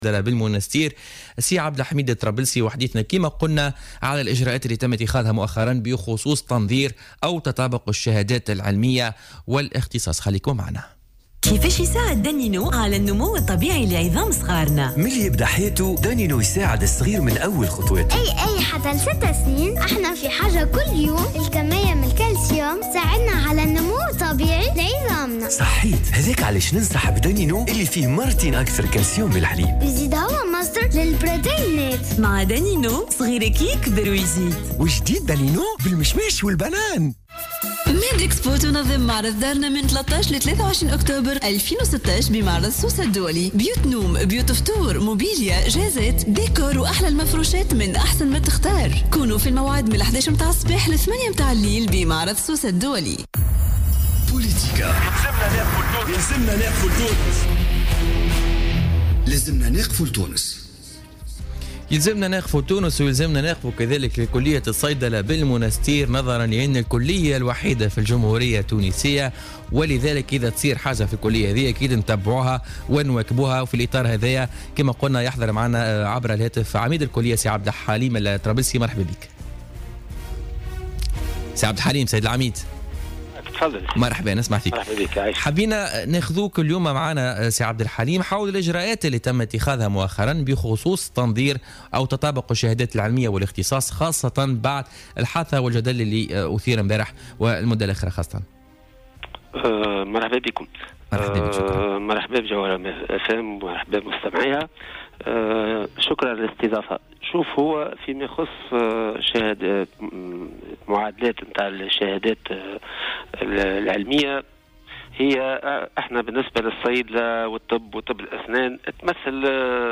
مداخلة له في بوليتيكا